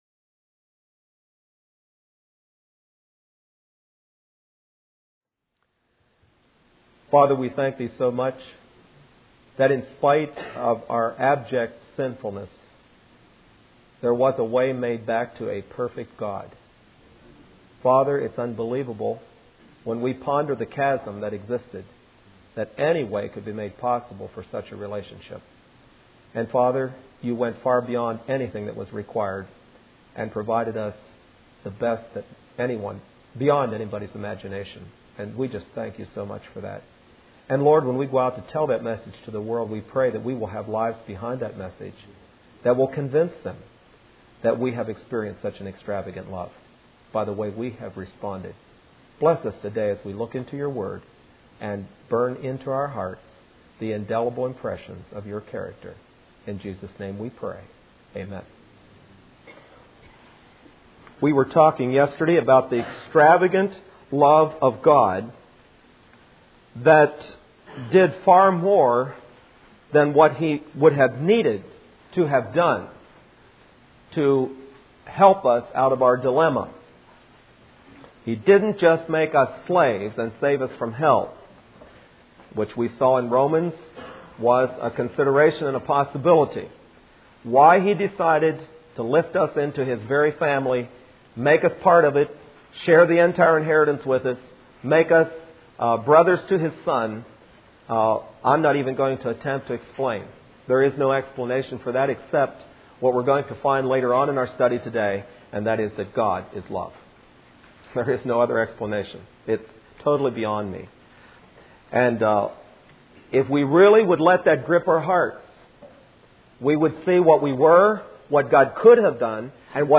Facing The Facts Service Type: Midweek Meeting Speaker